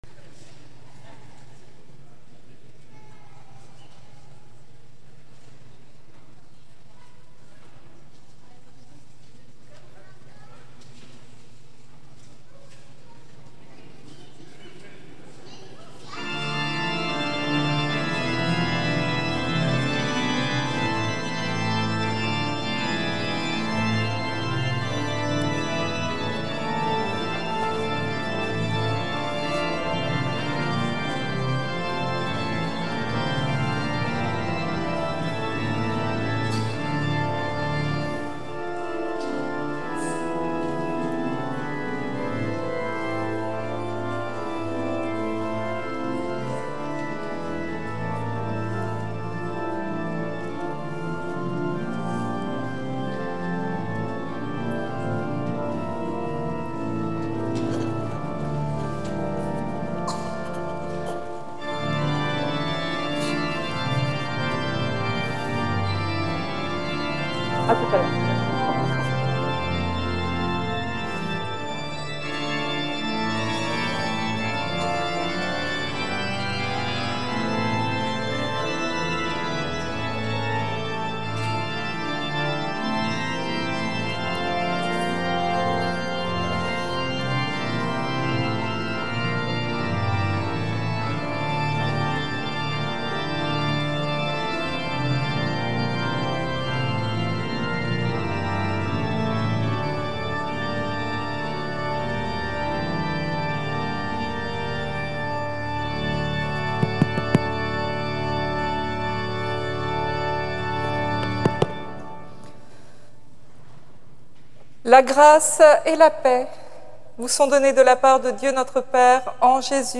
Écouter le culte entier (Télécharger au format MP3)
Suivre-le-Christ-pour-trouver-sa-demeure-Culte-du-9-mai-2021.mp3